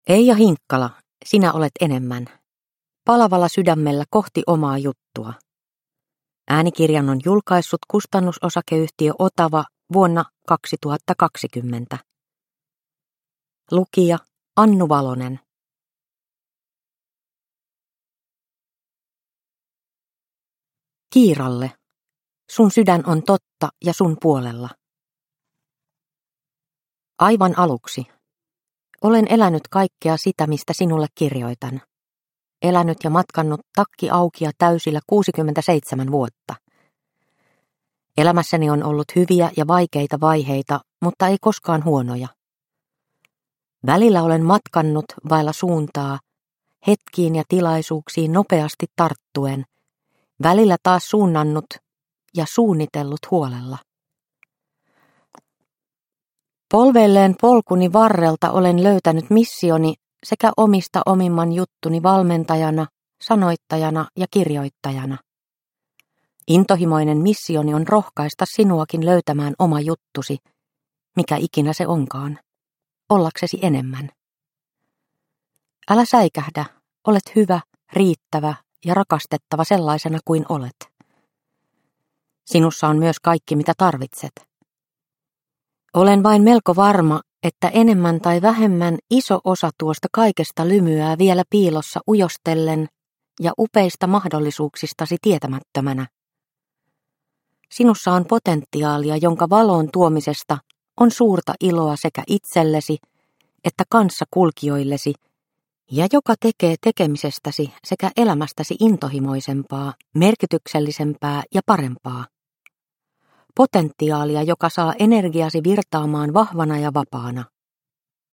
Sinä olet enemmän – Ljudbok – Laddas ner